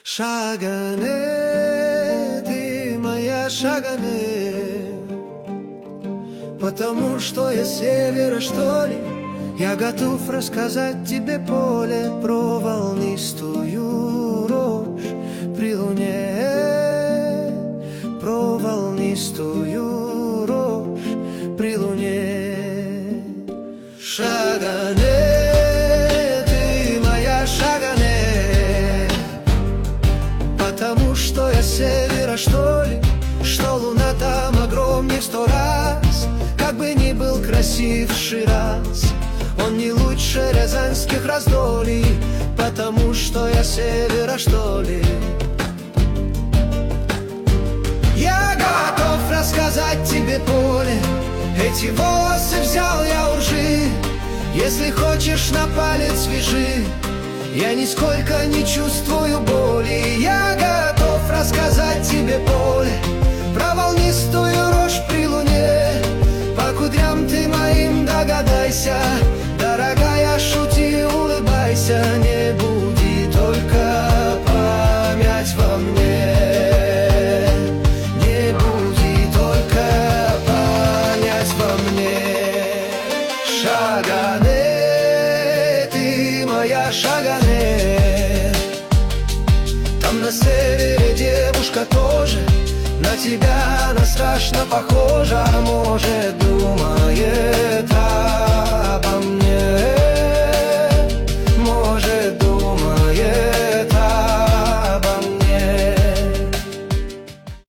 ИИ - ты моя Шаганэ версия